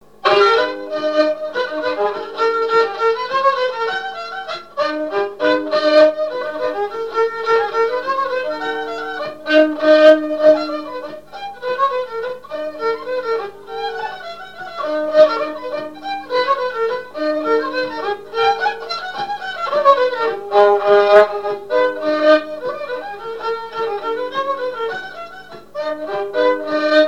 Mémoires et Patrimoines vivants - RaddO est une base de données d'archives iconographiques et sonores.
Chants brefs - A danser
danse : scottich trois pas
Pièce musicale inédite